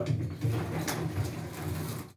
elevator_dooropen.wav